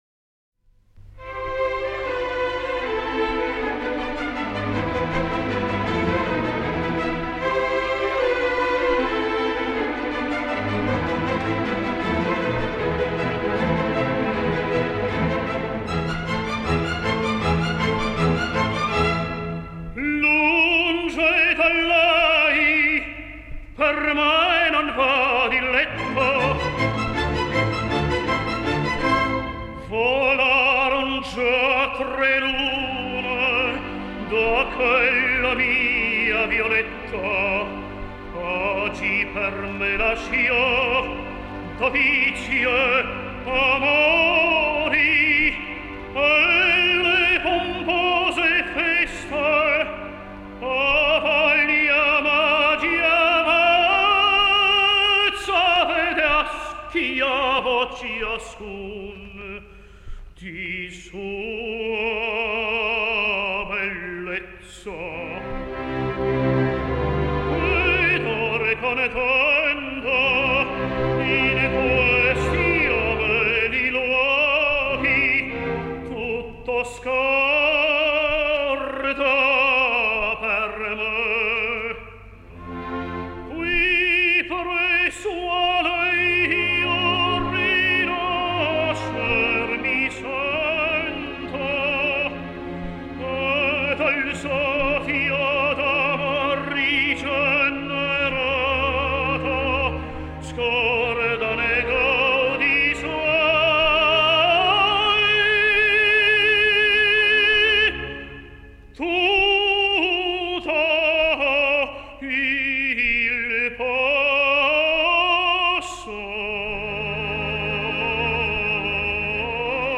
Великолепный тенор!